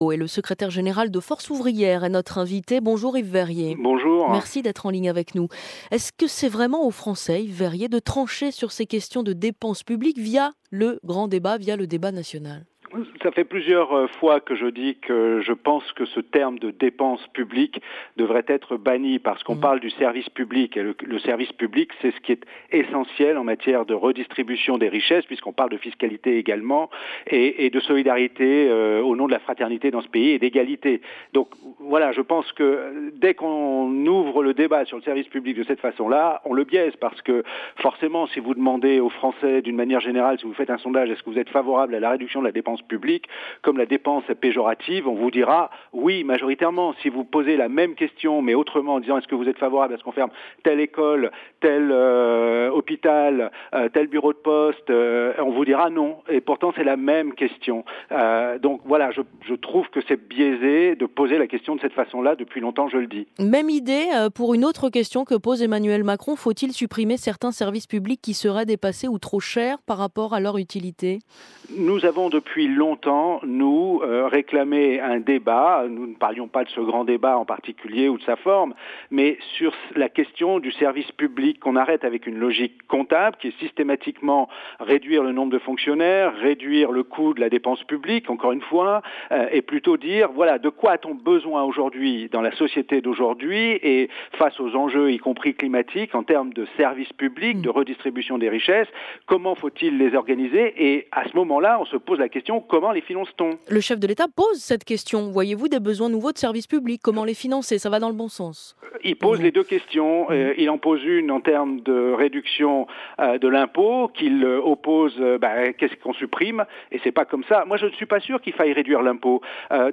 Yves Veyrier, Secrétaire général de Force Ouvrière, répondait aux questions qui lui étaient posées sur :franceinfo à propos du Grand débat national.